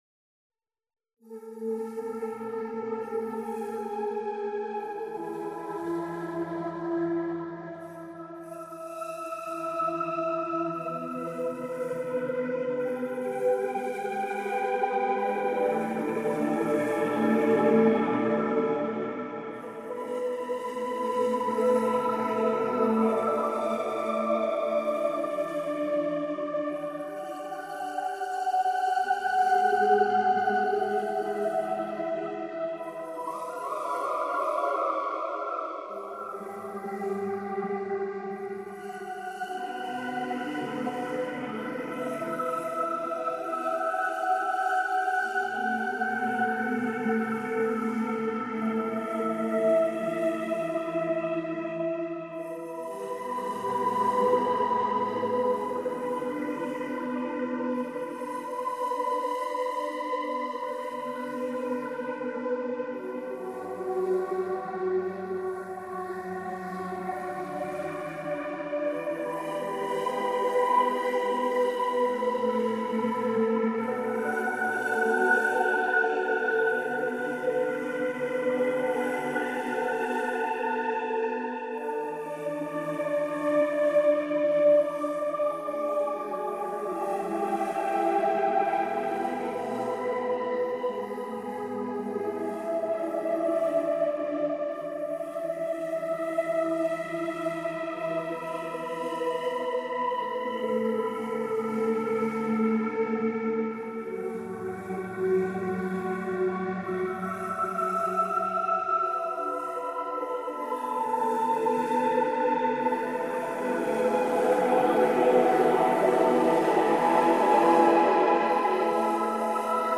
癒し効果バッチリ 穏やかなメロディと自然音をミックスした、リラックスできる音源です。
高品質な音質 プロのサウンドエンジニアが手掛けたクリアな音質で、どんなプロジェクトにもマッチします。